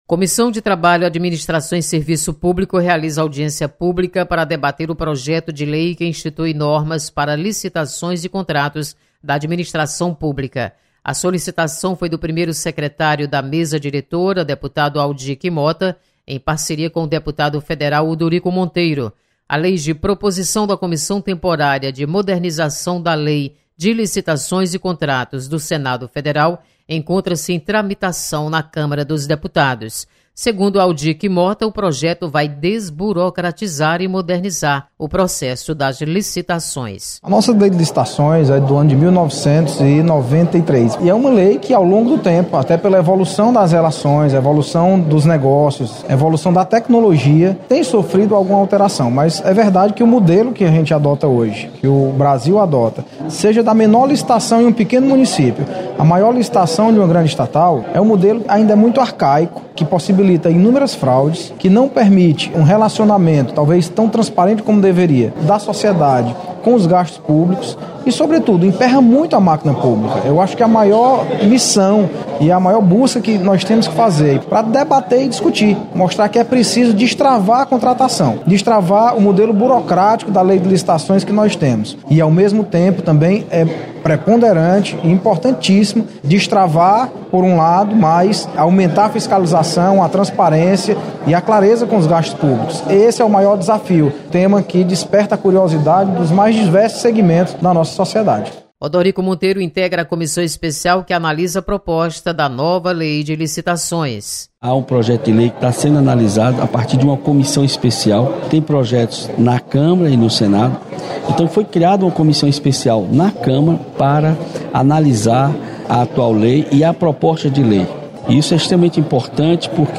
Lei das licitações é debatida em audiência pública. Repórter